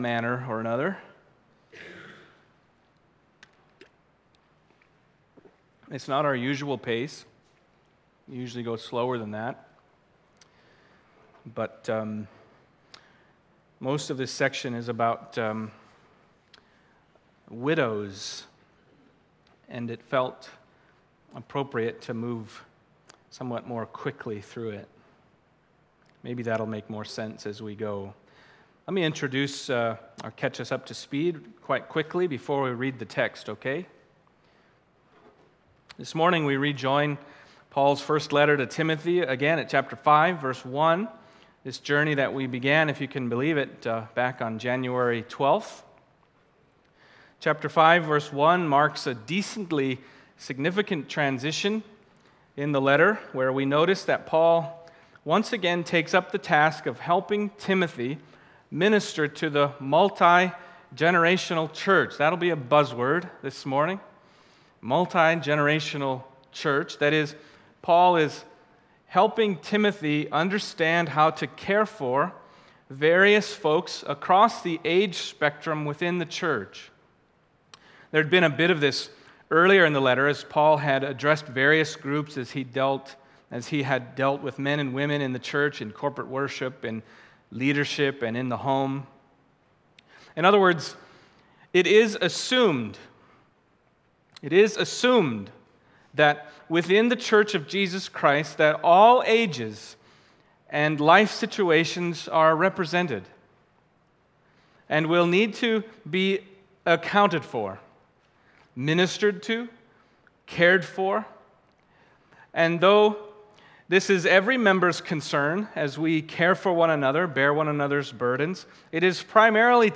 Passage: 1 Timothy 5:1-16 Service Type: Sunday Morning